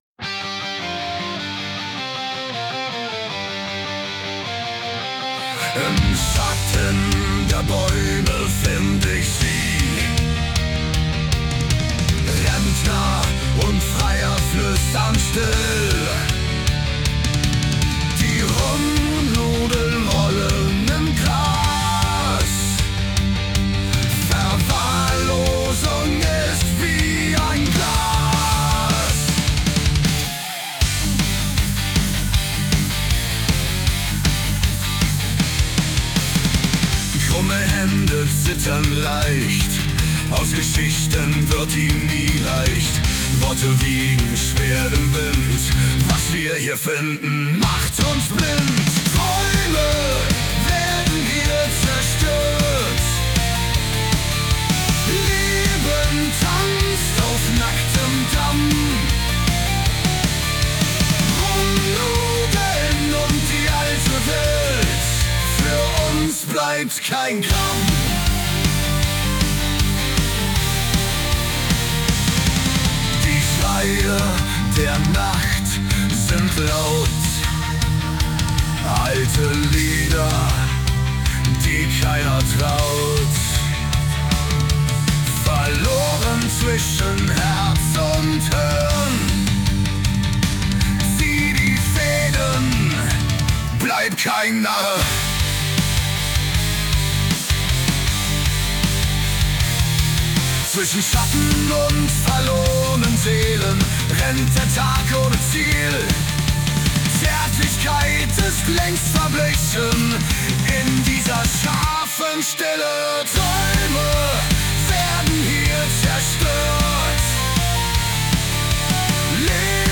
Deutschrockband